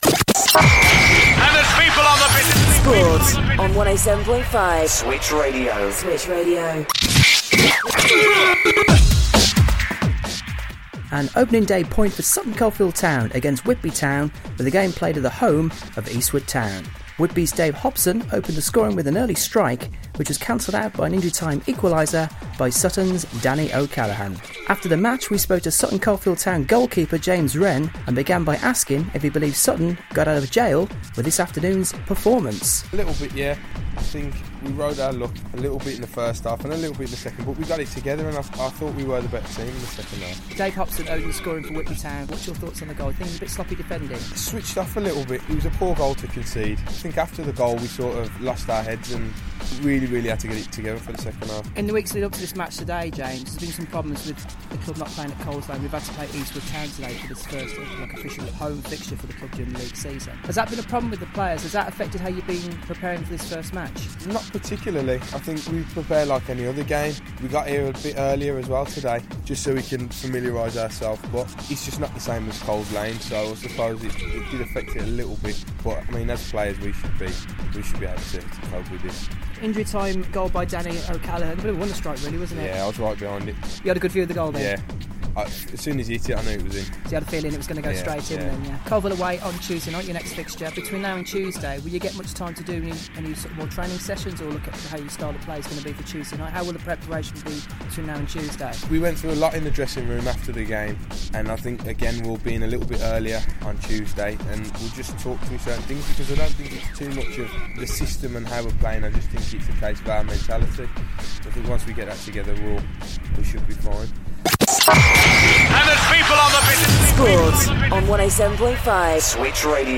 Post match reaction